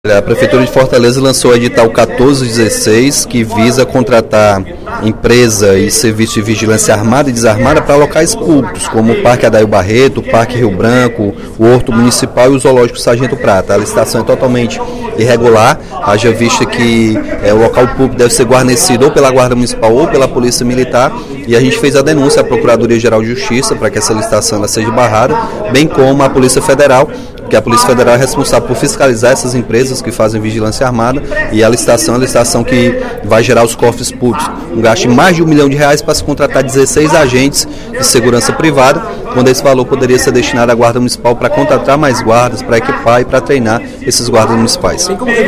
Durante o primeiro expediente da sessão plenária desta sexta-feira (27/03), o deputado Capitão Wagner (PR) denunciou irregularidades no edital lançado pela Prefeitura de Fortaleza para contratação de empresa para serviços de vigilância armada e desarmada em locais públicos, como o Parque Adahil Barreto, o Parque Rio Branco e o Zoológico Sargento Prata.